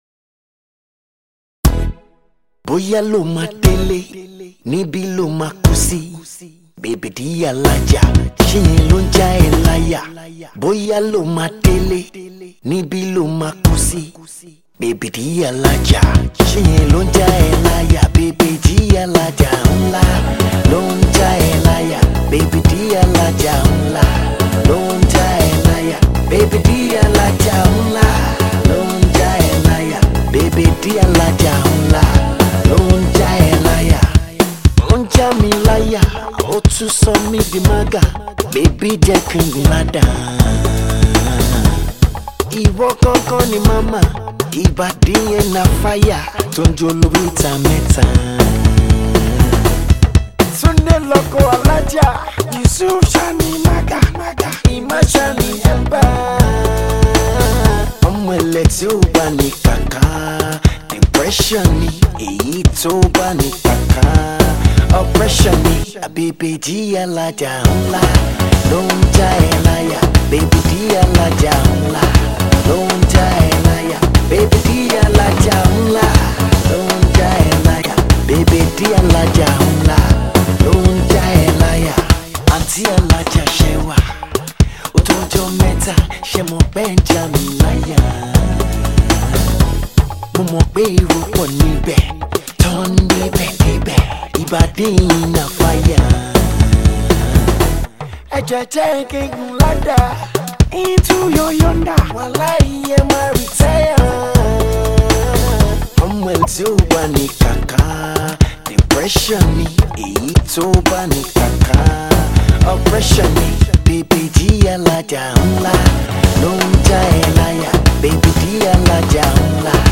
traditional beats, juju and Afro-pop
perfect for the dance floor